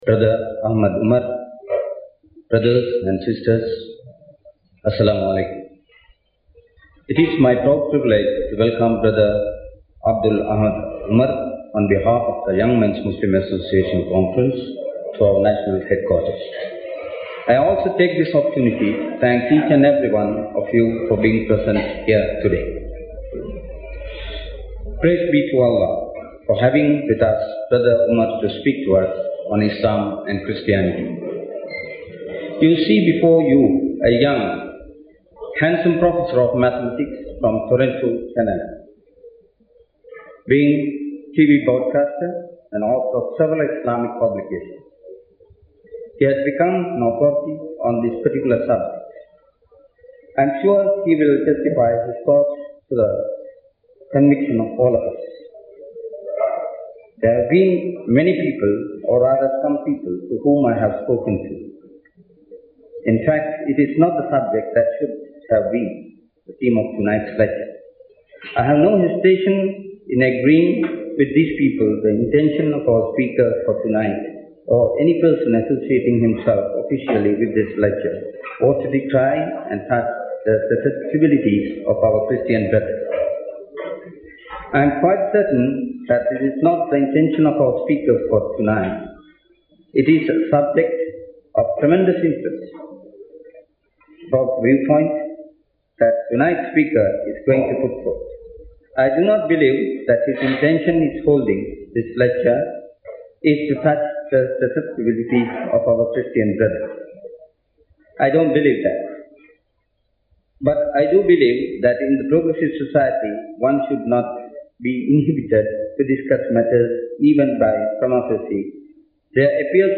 Lectures in English